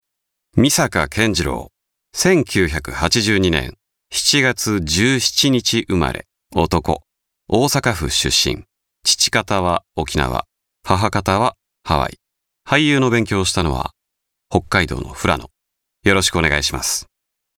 ボイス